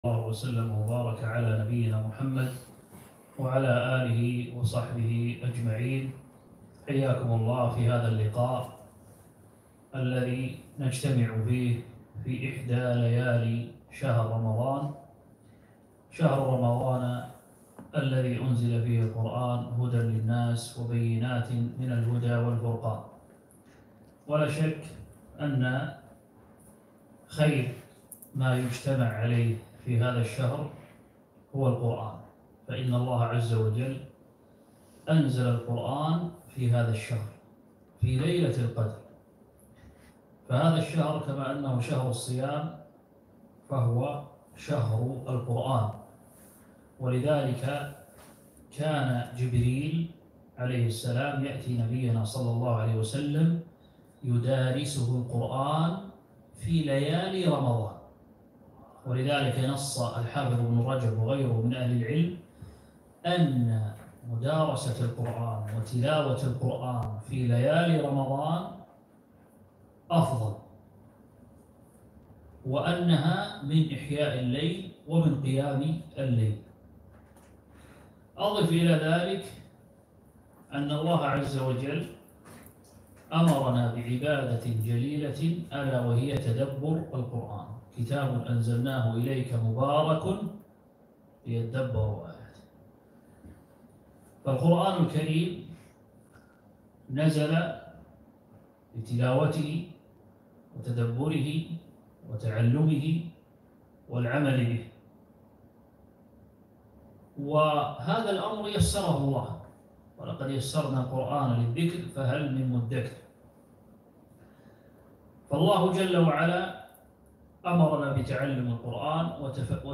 محاضرة - مقاصد سور القرآن الكريم